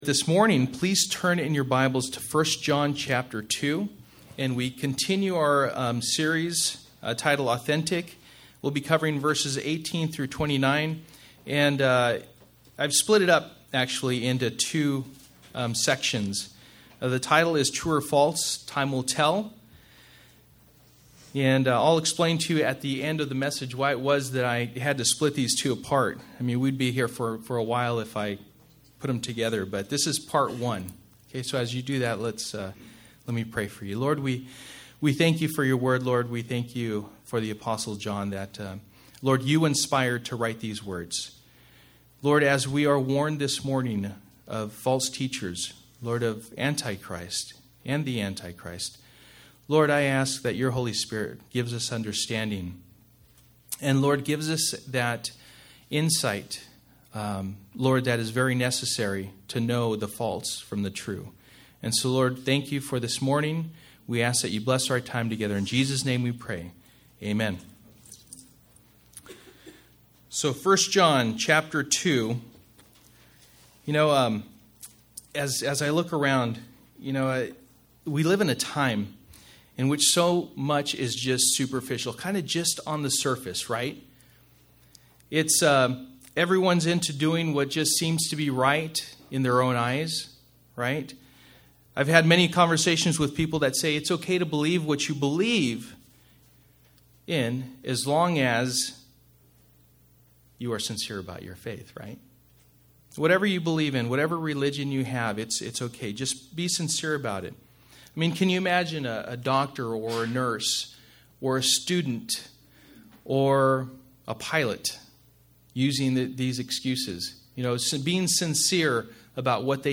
Passage: 1 John 2:18-29 Service: Sunday Morning